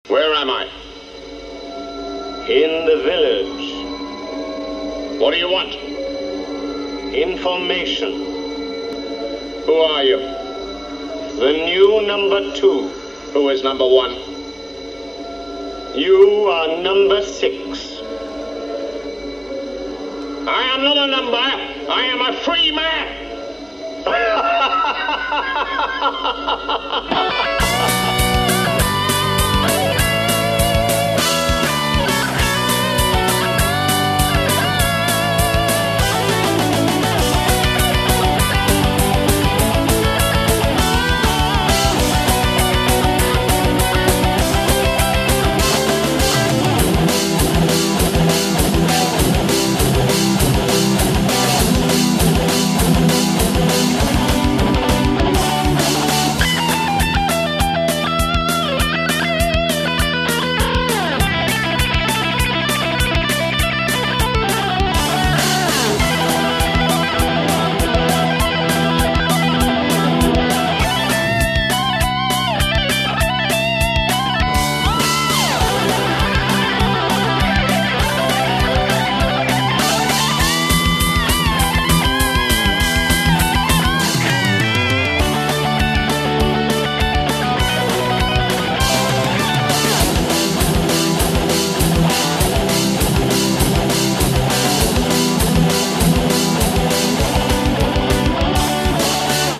[Rock]